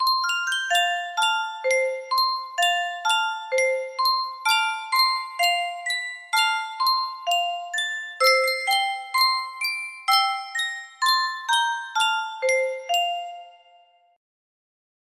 Yunsheng Music Box - Santa Claus, Indiana, USA 2023 music box melody
Full range 60